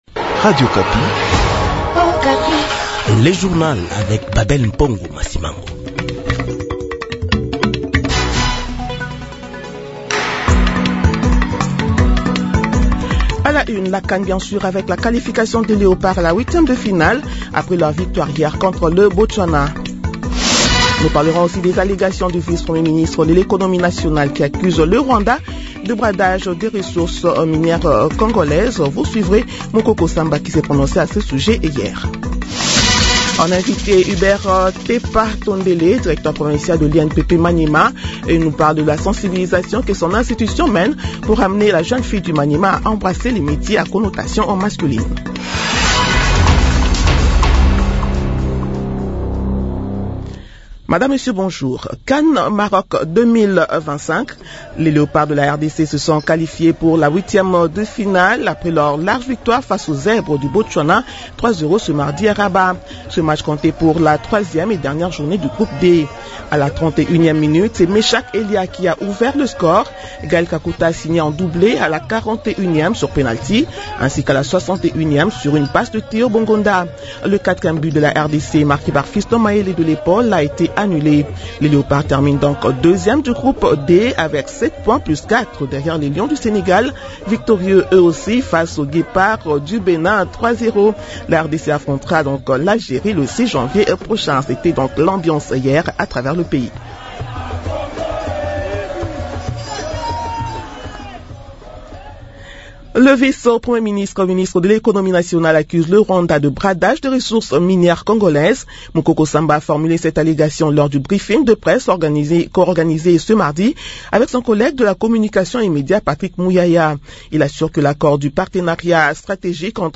Journal de 7h de ce mercredi 31 décembre 2025